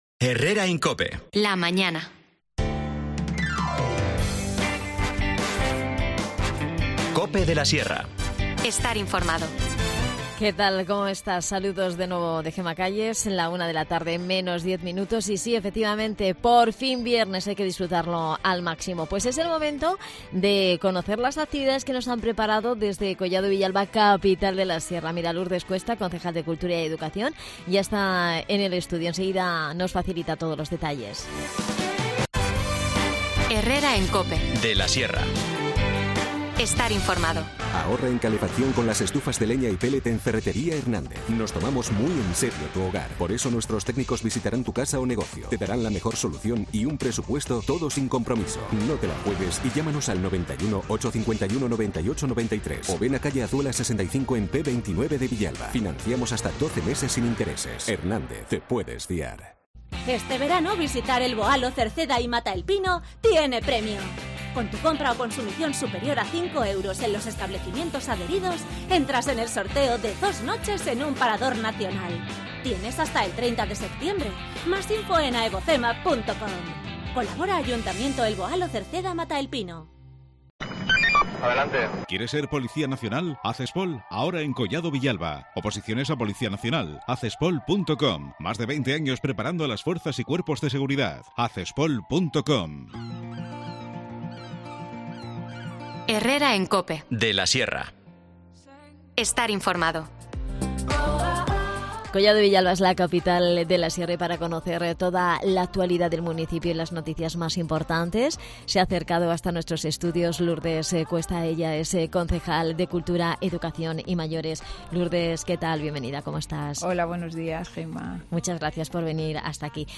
Redacción digital Madrid - Publicado el 08 sep 2023, 13:34 - Actualizado 08 sep 2023, 14:07 2 min lectura Descargar Facebook Twitter Whatsapp Telegram Enviar por email Copiar enlace Nos visita Lourdes Cuesta, concejal de Cultura y Educación de Collado Villalba, Capital de la Sierra, para repasar la actualidad en el municipio, que pasa esta semana por la 'vuelta al cole'.